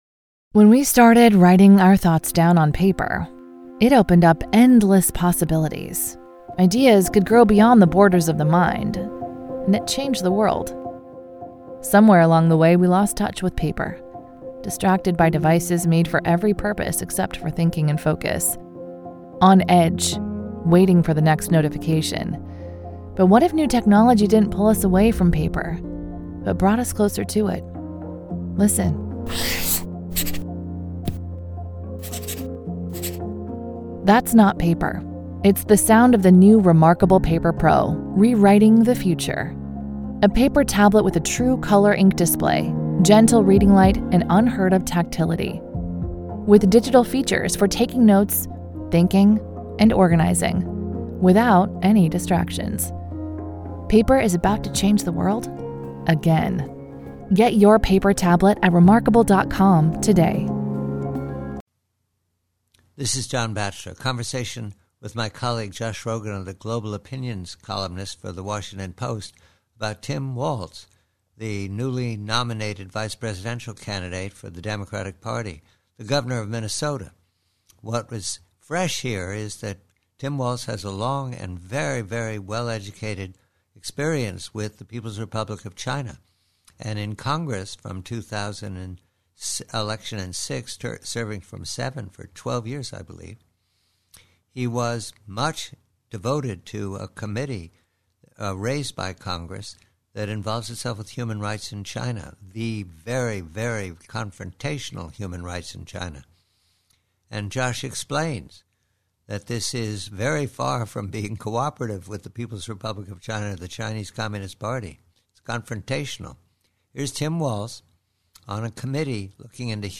PREVIEW: PRC: TIM WALZ: Conversation with colleague Josh Rogin of the Washington Post regarding Tim Walz's long-standing work on human rights in the PRC.